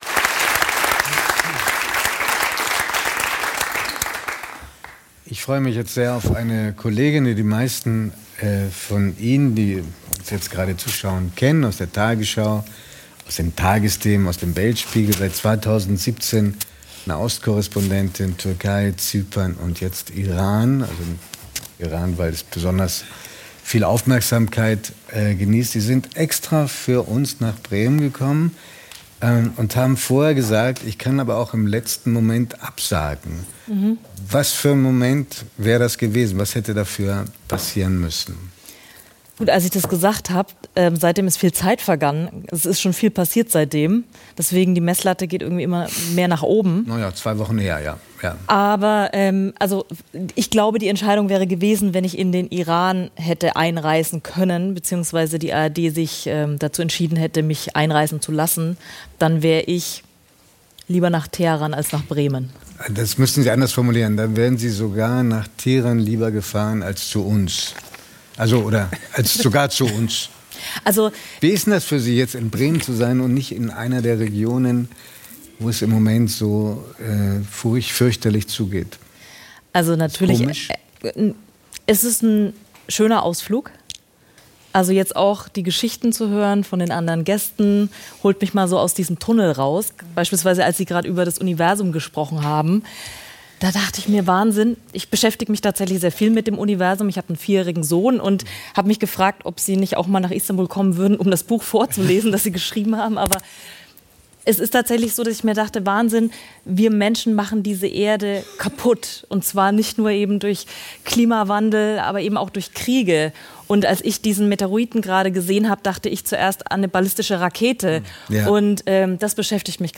ARD-Korrespondentin ~ 3nach9 – Der Talk mit Judith Rakers und Giovanni di Lorenzo Podcast